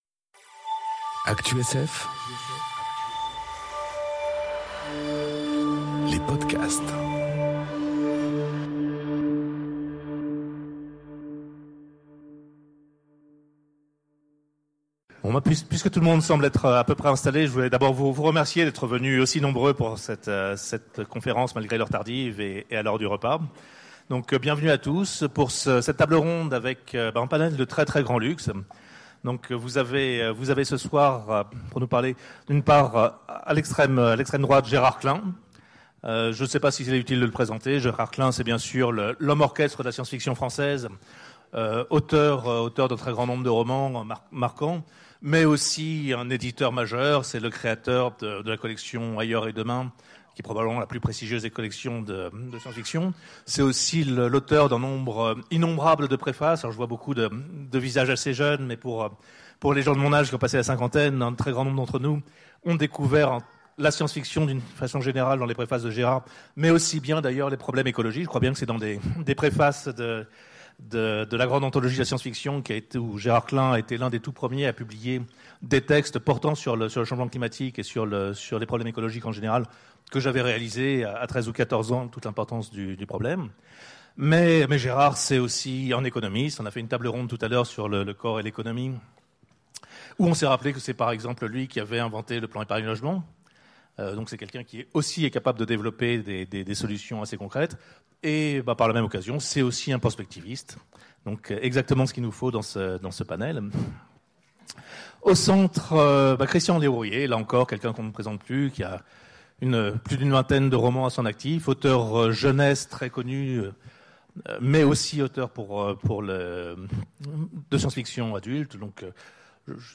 Conférence Quelles pistes institutionnelles face au changement climatique ? enregistrée aux Utopiales 2018